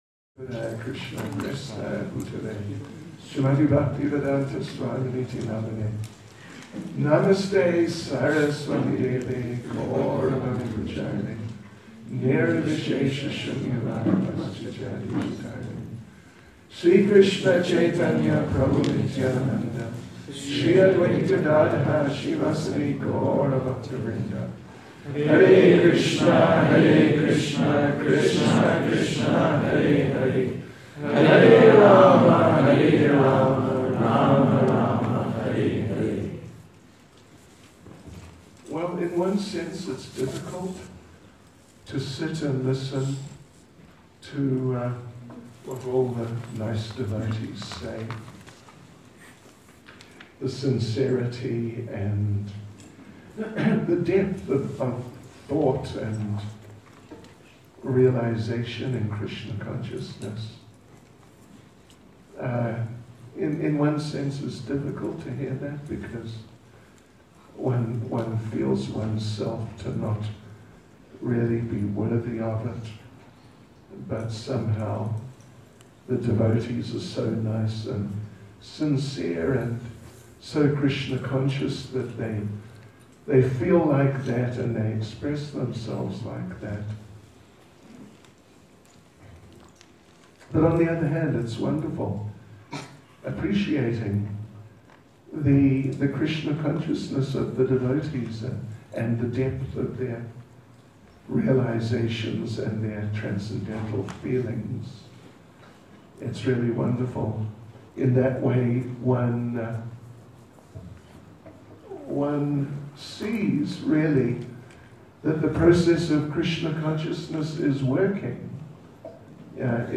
Srila Prabhupada, The Foundation of Our Lives(Vyasa Puja Address 2015)